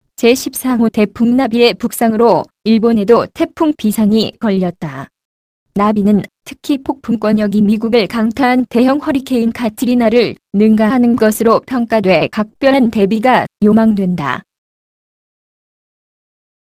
Texte de d�monstration lu par Narae (Nuance RealSpeak; distribu� sur le site de Nextup Technology; femme; chor�en)